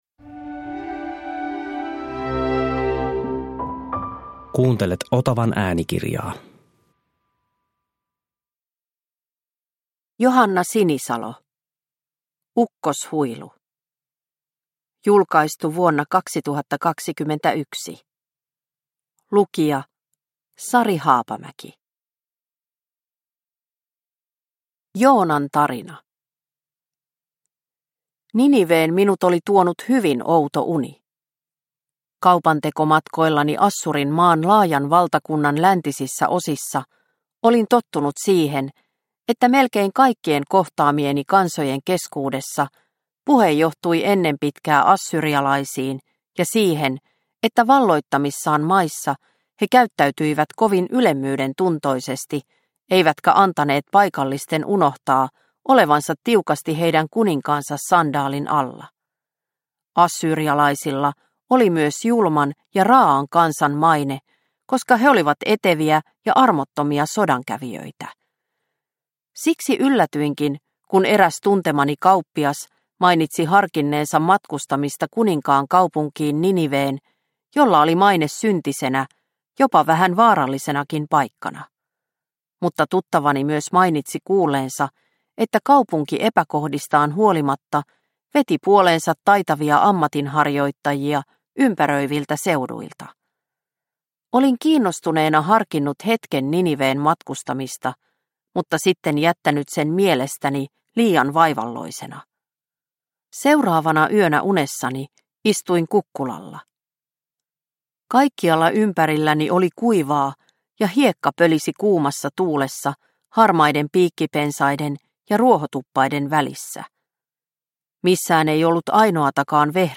Ukkoshuilu – Ljudbok – Laddas ner